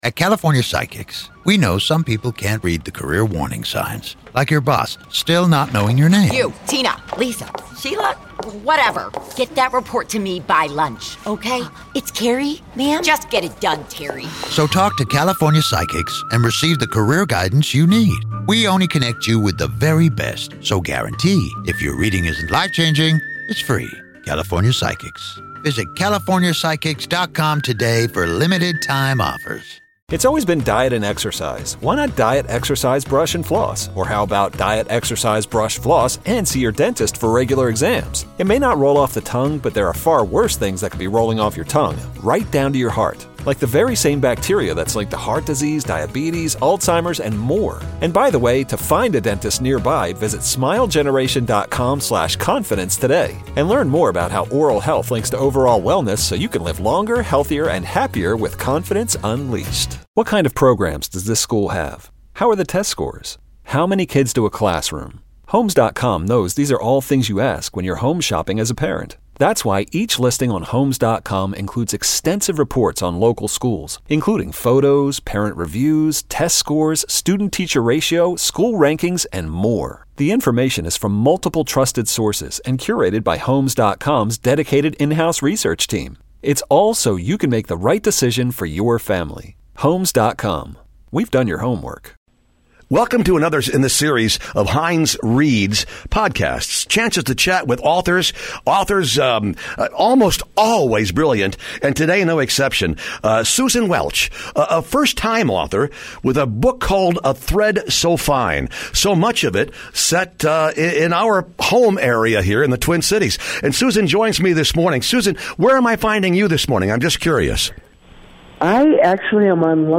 reads Susan Welch's A Thread So Fine